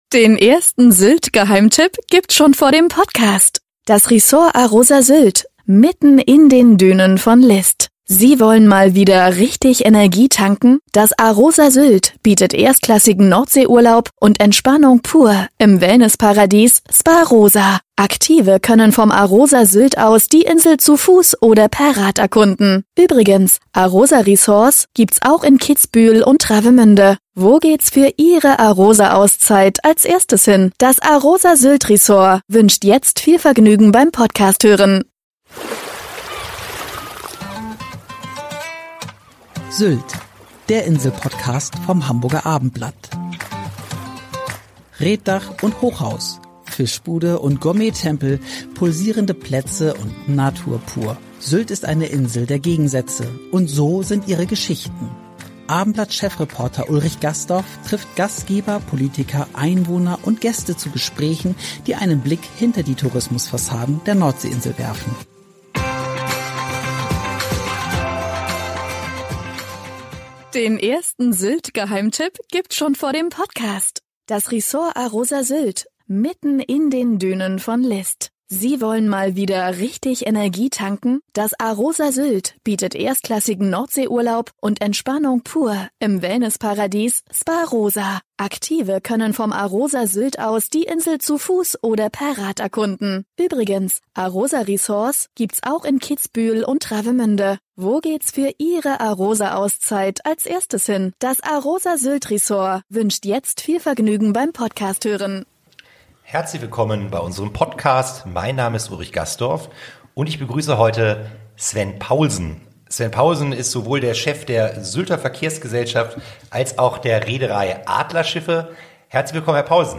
Gespräche, die einen Blick hinter die Tourismus-Fassaden der Nordsee-Insel werfen